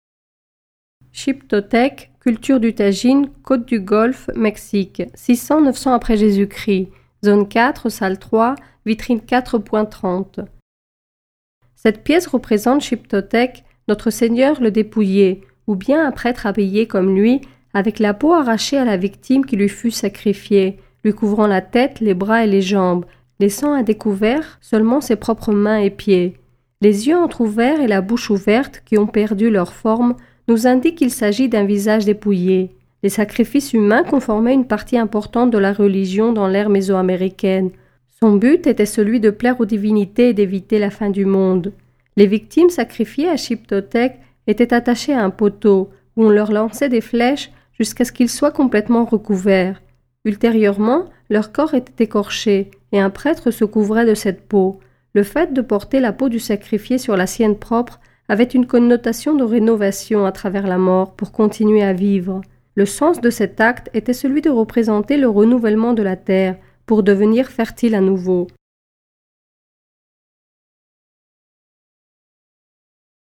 Audioguides par pièces